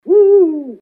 Угу филина